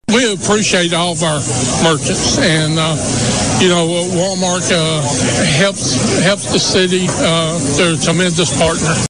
Wal-Mart holds Grand Rebranding Ribbon Cutting Ceremony
Mayor Randy Brundige added